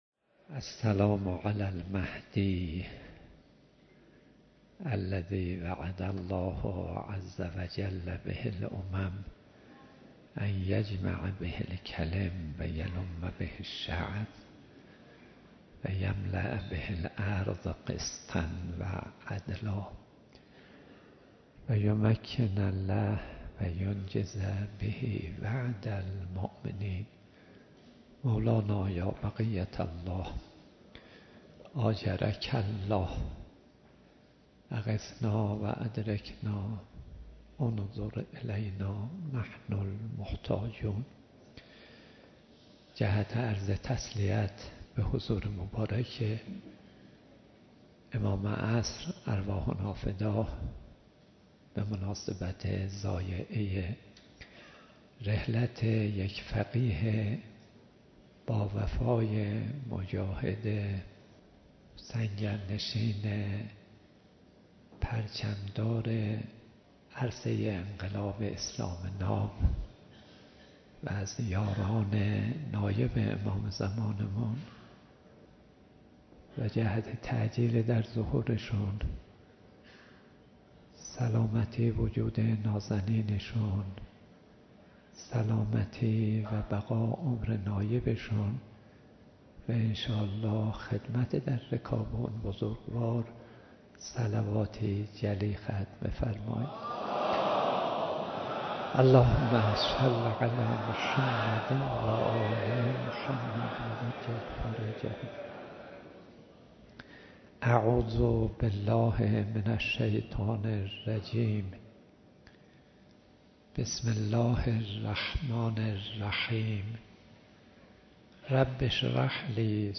مراسم ترحیم و بزرگداشت آیت‌الله هاشمی شاهرودی
سخنرانی حجت الاسلام والمسلمین صدیقی